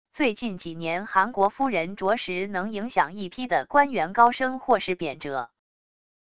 最近几年韩国夫人着实能影响一批的官员高升或是贬谪wav音频生成系统WAV Audio Player